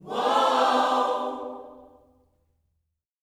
WHOA-OHS 5.wav